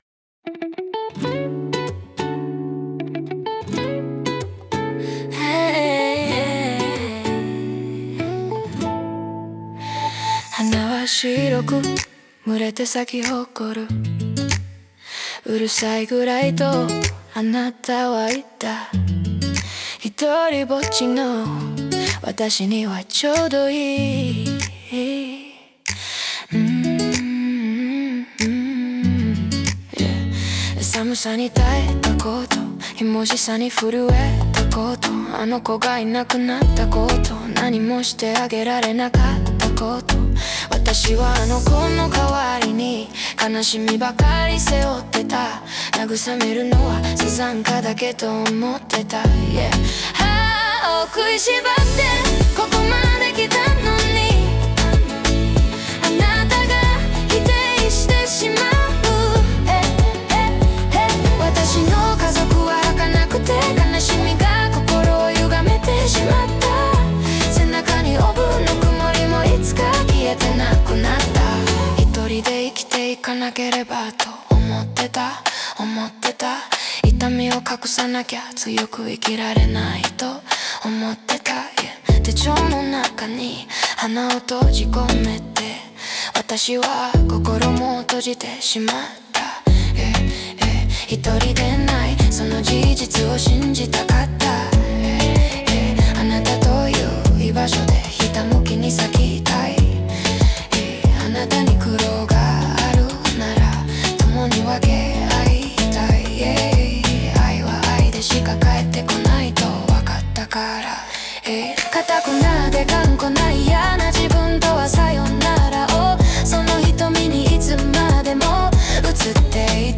音本倶楽部／コーラス部（唄のみ） リンク 作品ページ（読書アプリ/本文）朗読動画 動画 歌詞花は白く群れて咲き誇る うるさいぐらいとあなたはいった ひとりぼっちの私にはちょうどいい 寒さにたえたこと ひもじさにふるえたこと あの子がいなくな